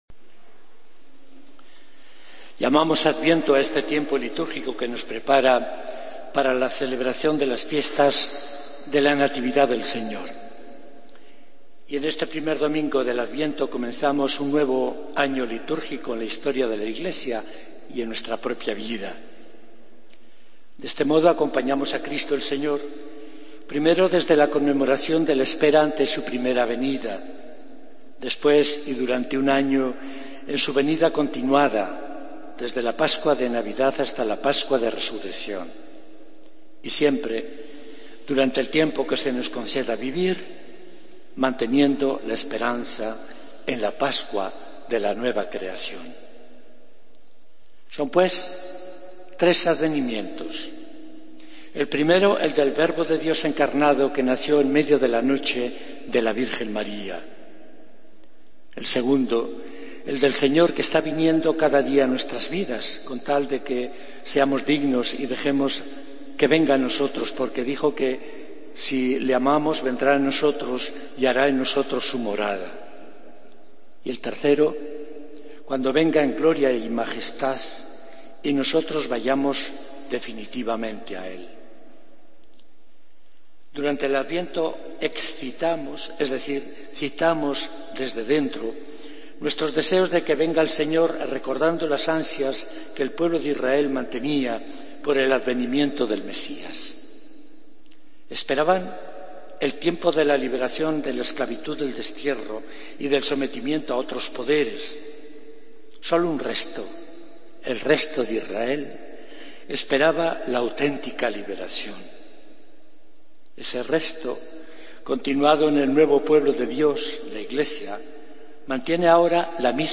Homilía del 30 de Noviembre de 2013